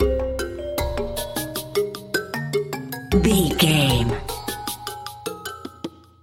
Aeolian/Minor
percussion
bongos
congas
hypnotic
medium tempo